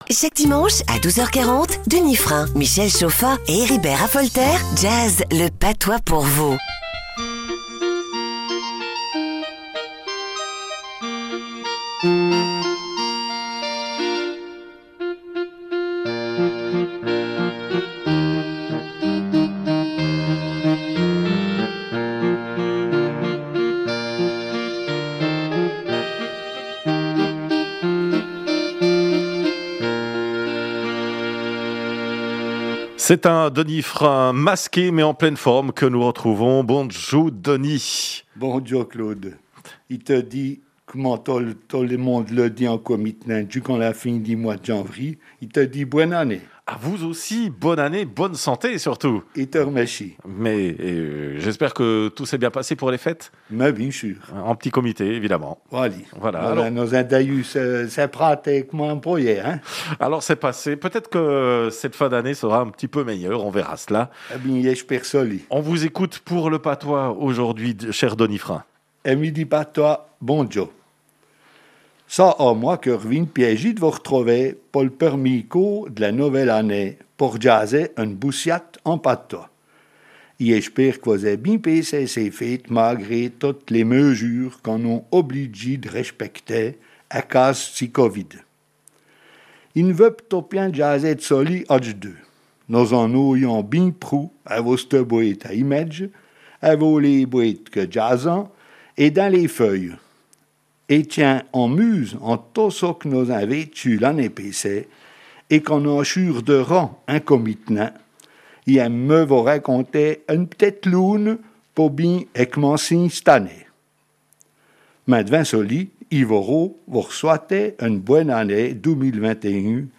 RFJ 17 janvier 2021 Radio Fréquence Jura RFJ 17 janvier 2021 Rubrique en patois Auteur